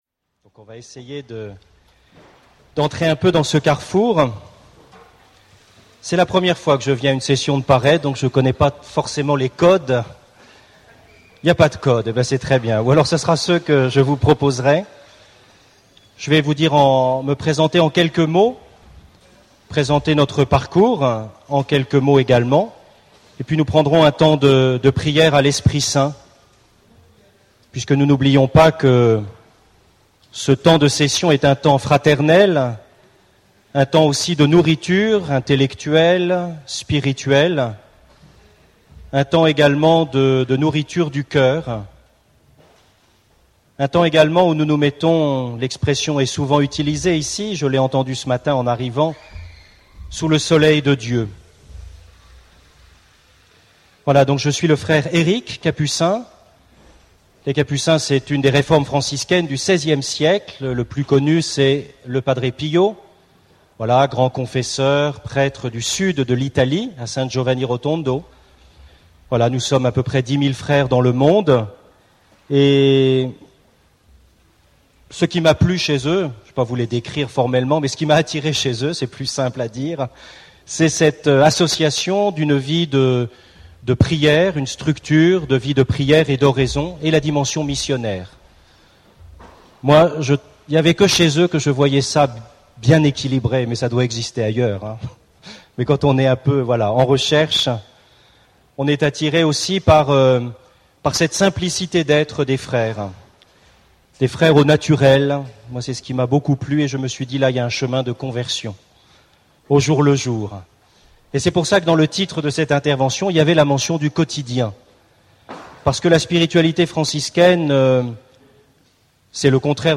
Enseignement
Session famille 3 (du 4 au 9 août 2012)